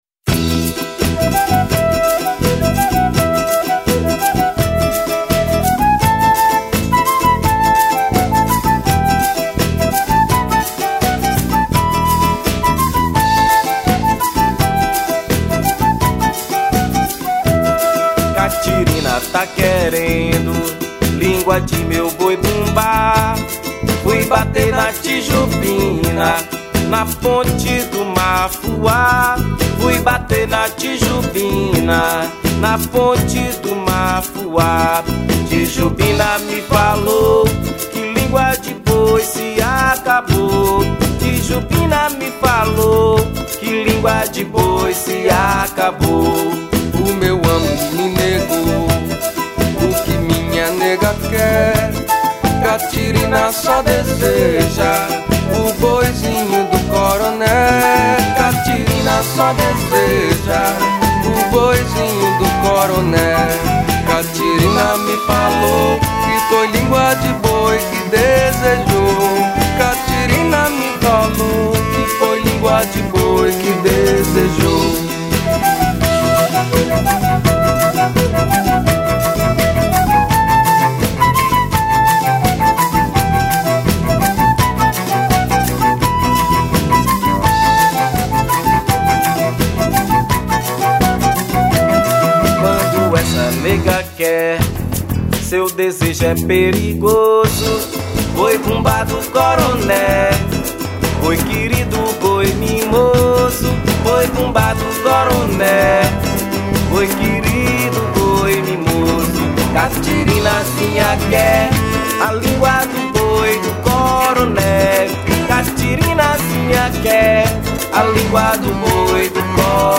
02:59:00   Boi Bumbá